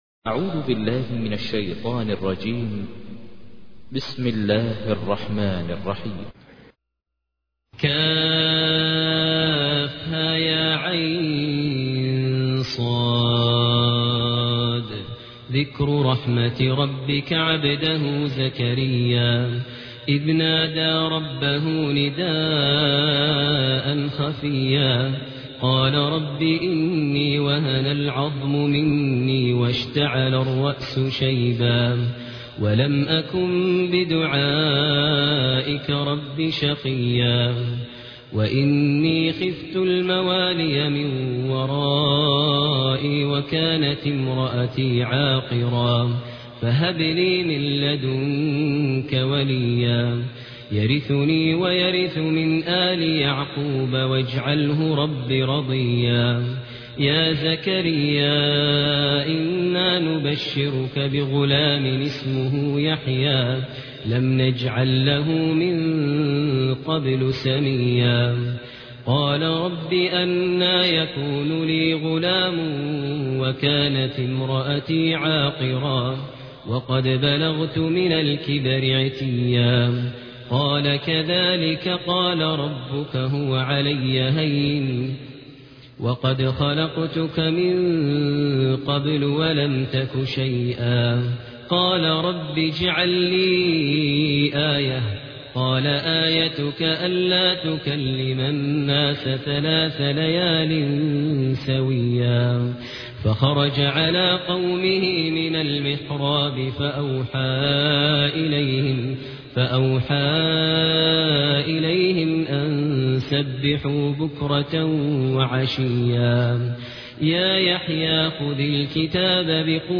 تحميل : 19. سورة مريم / القارئ ماهر المعيقلي / القرآن الكريم / موقع يا حسين